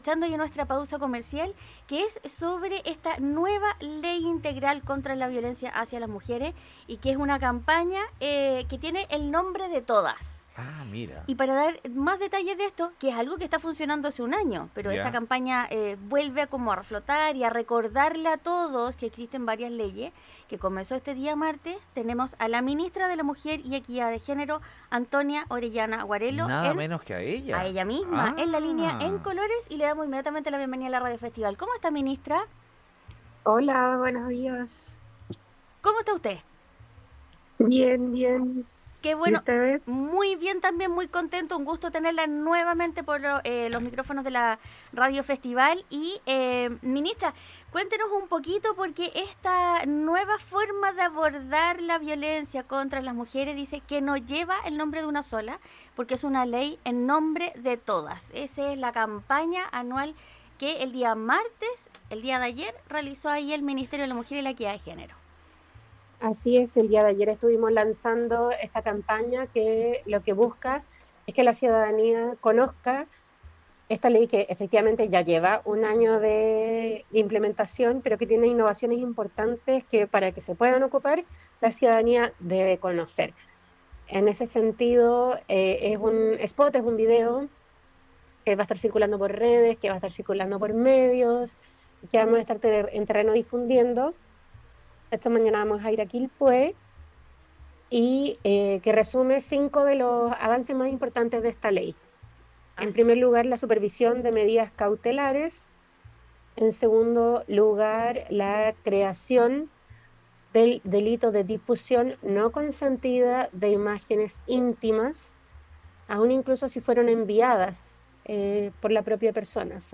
La Ministra Antonia Orellana conversó con Radio Festival sobre el lanzamiento de esta Campaña con respecto a que la ciudadanía conozca la Ley Integral contra la violencia hacia las mujeres y exija su cumplimiento.